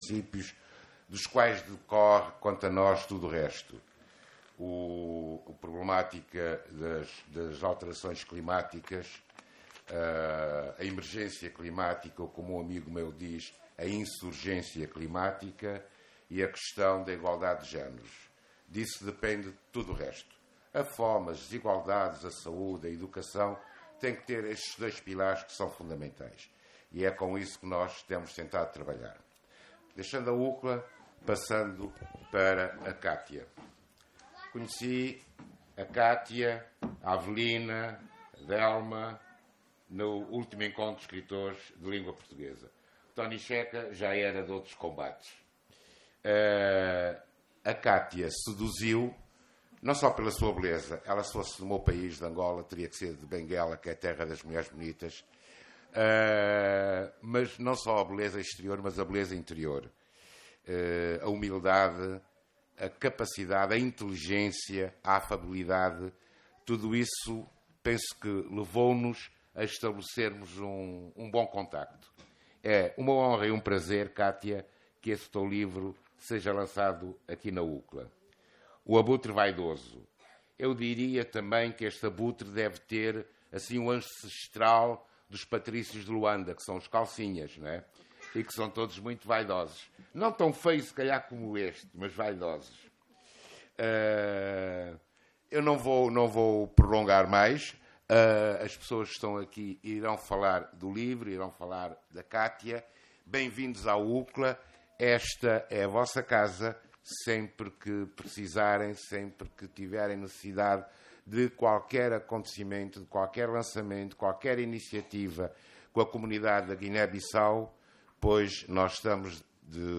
Ouça aqui a reportagem áudio do lançamento do livro “O Abutre Vaidoso”